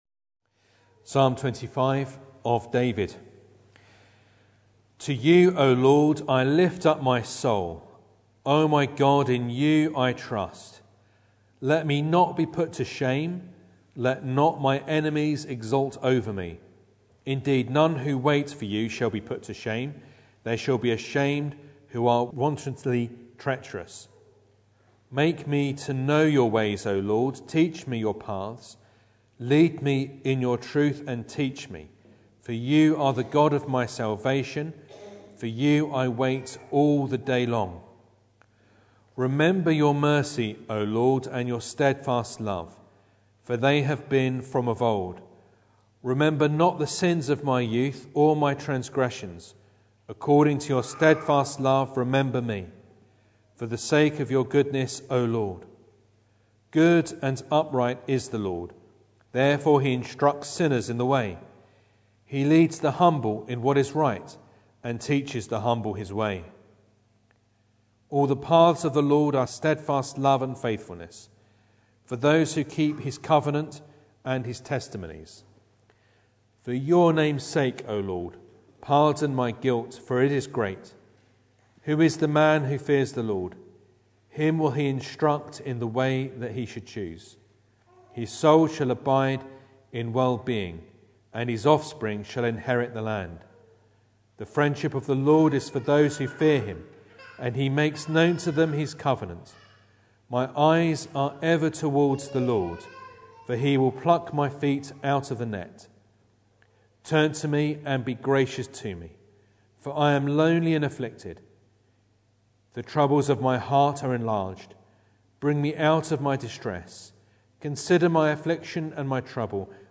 Psalm 25 Service Type: Sunday Evening Bible Text